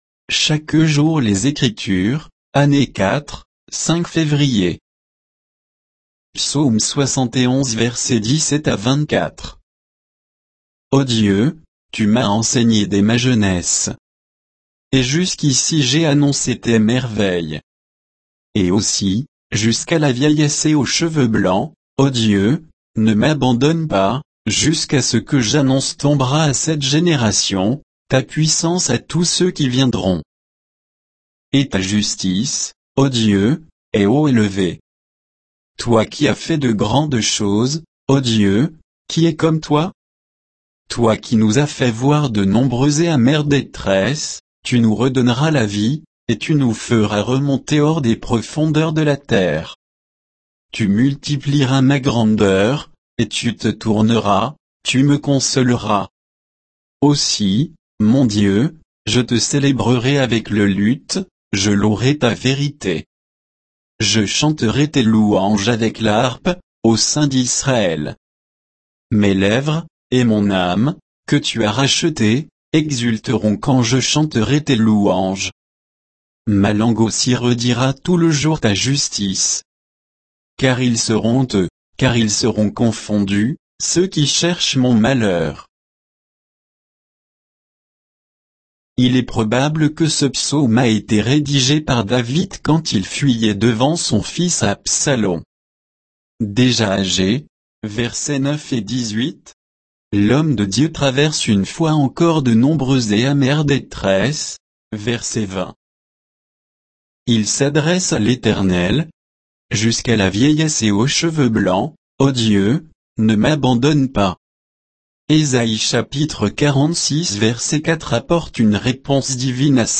Méditation quoditienne de Chaque jour les Écritures sur Psaume 71, 17 à 24